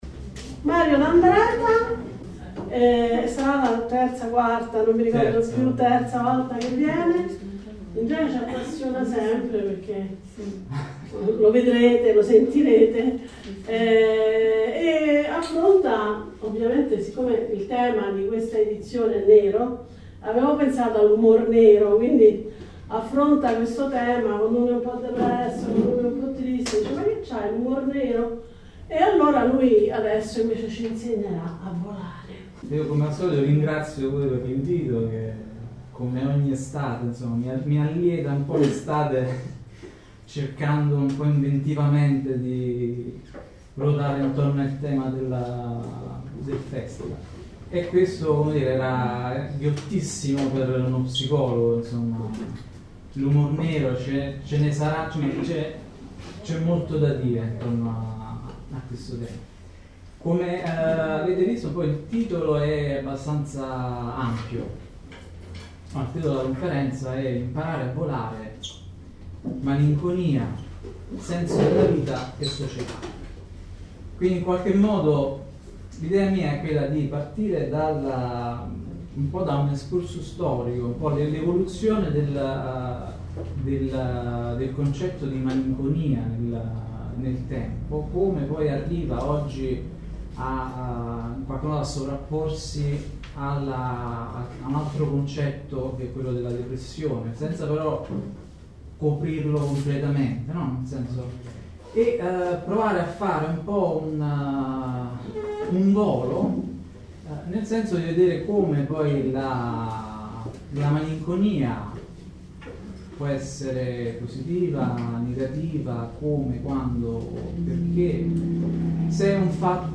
Una conferenza tenuta nell’ambito dell’edizione 2018 del “ Land Art Festival al Furlo “.